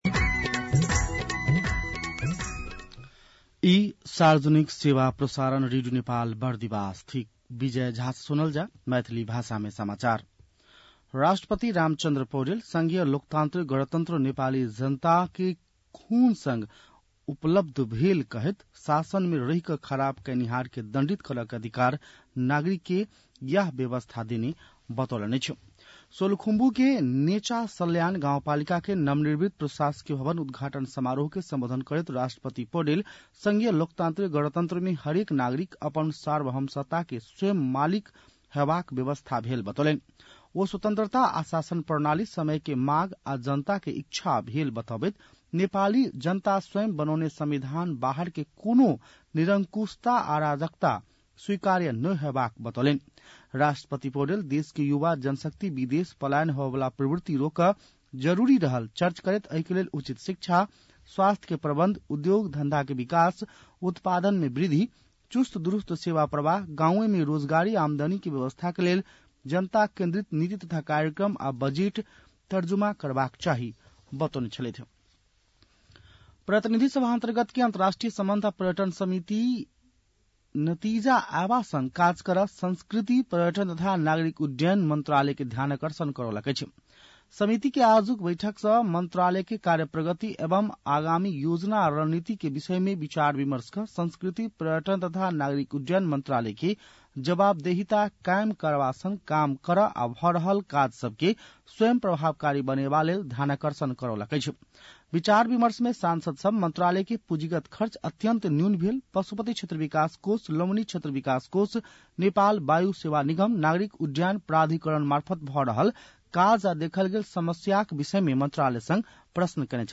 मैथिली भाषामा समाचार : ११ चैत , २०८१